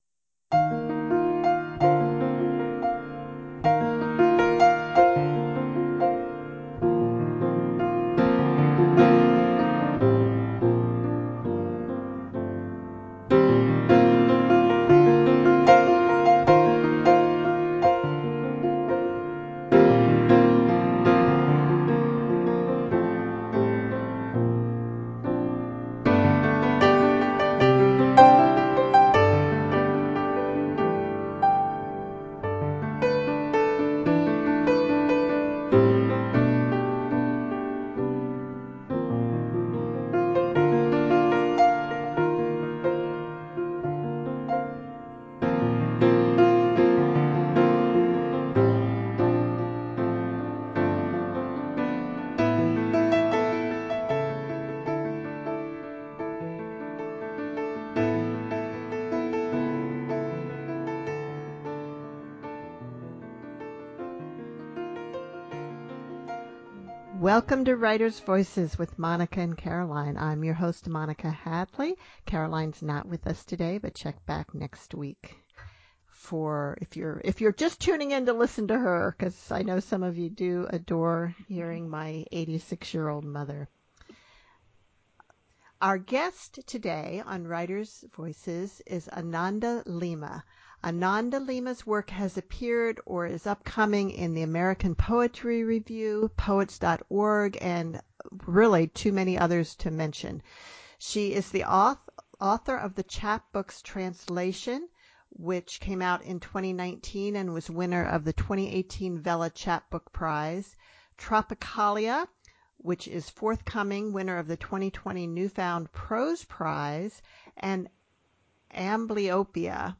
In our wide-ranging conversation, we discuss motherhood, migration, and writing.